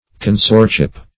Search Result for " consortship" : The Collaborative International Dictionary of English v.0.48: Consortship \Con"sort*ship\ (k[o^]n"s[^o]rt*sh[i^]p), n. The condition of a consort; fellowship; partnership.